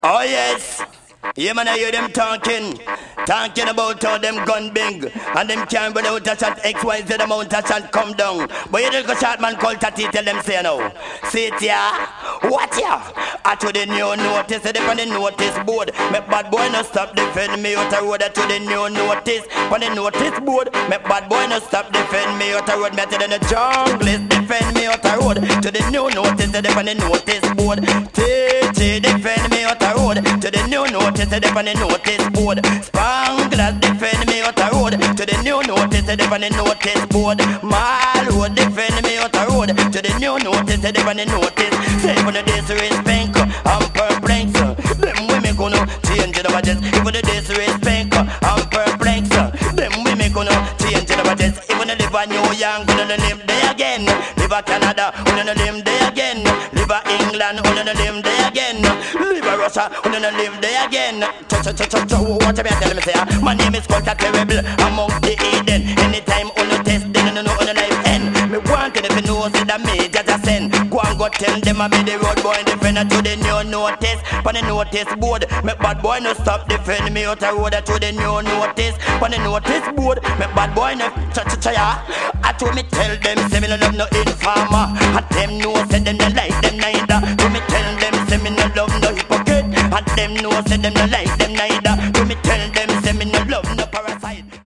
get this pure fya brand of reggae in your system.